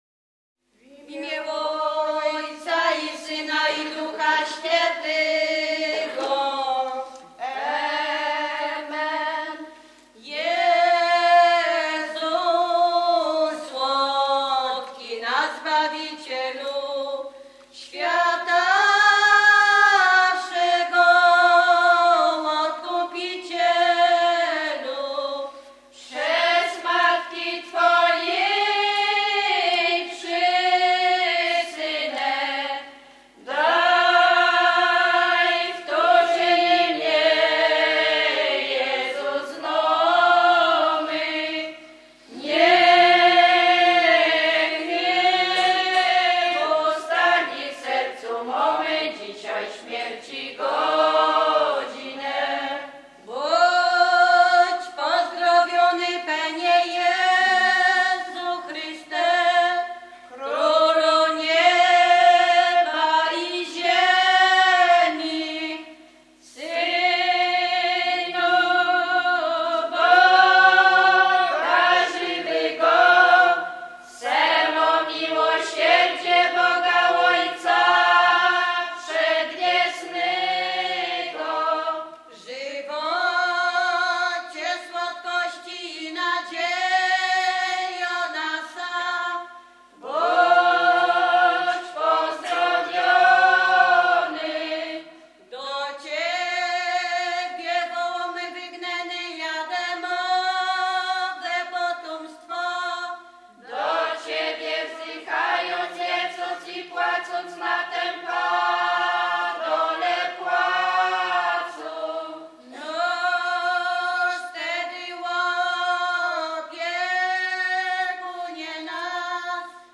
Zespół "Jarzębina" z Kocudzy
Roztocze
województwo lubelskie, powiat janowski, gmina Dzwola, wieś Kocudza
nabożne katolickie różaniec